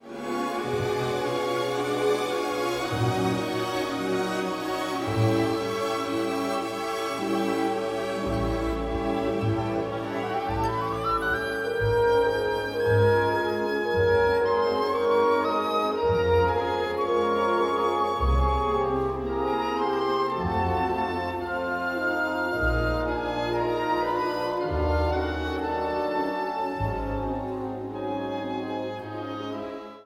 Tchaïkovski : Roméo et Juliette, Ouverture-fantaisie (version 1880)
L’Ouverture-fantaisie est en réalité un poème symphonique qui sublime les thèmes de la célèbre tragédie : on est bouleversé par l’intensité émotionnelle d’une des œuvres les plus emblématiques du romantisme.